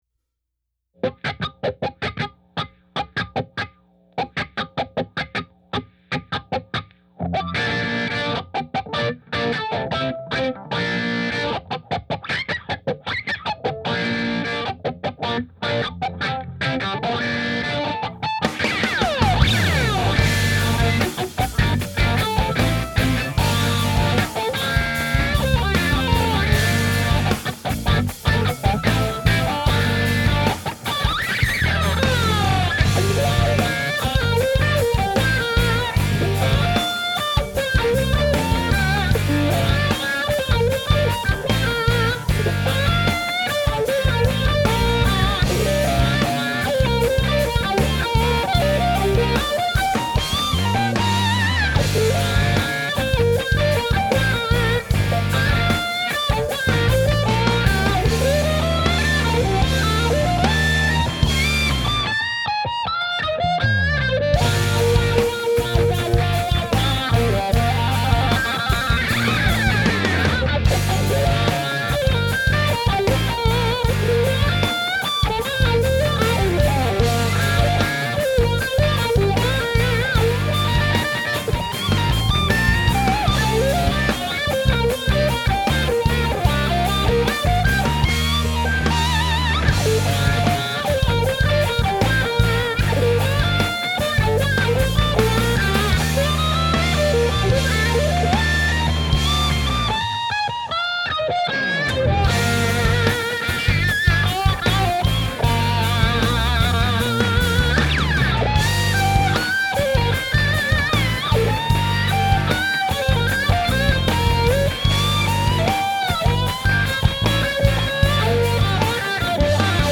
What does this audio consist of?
Voicing: Violin